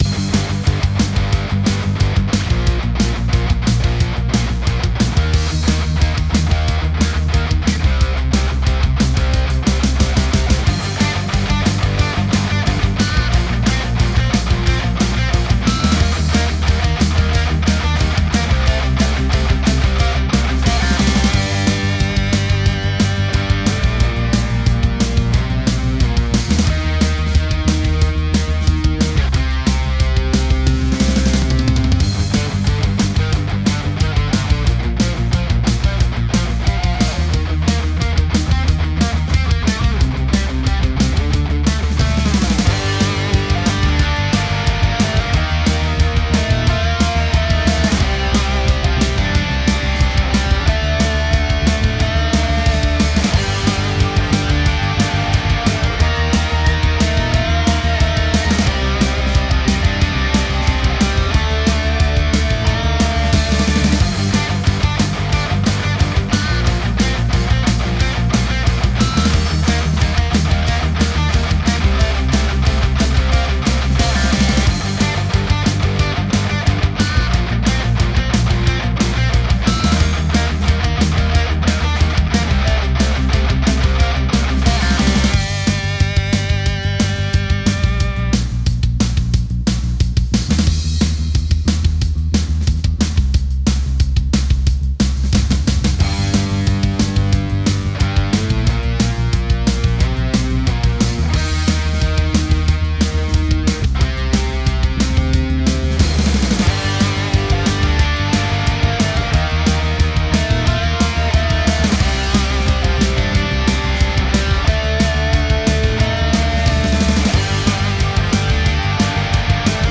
Кто добротно сведет панк за 50$?
На бас гитаре - Parker Вложения Rock30.mp3 Rock30.mp3 8,8 MB · Просмотры: 933